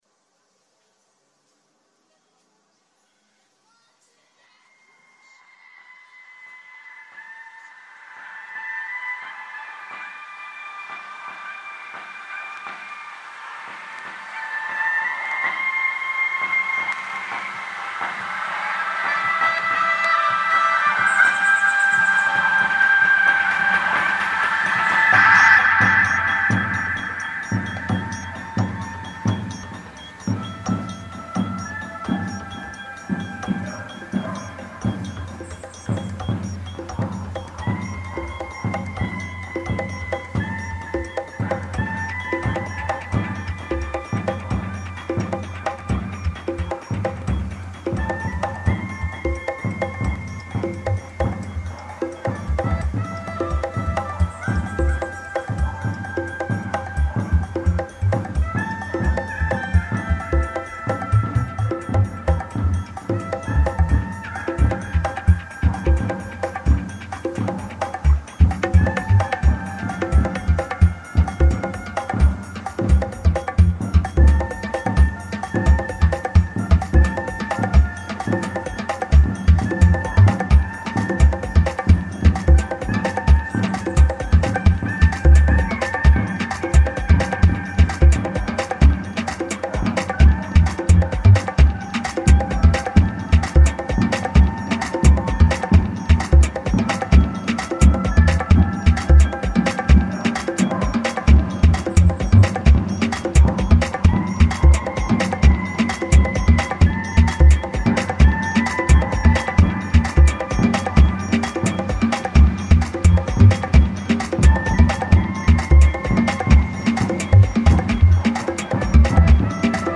Mexico City street band during lockdown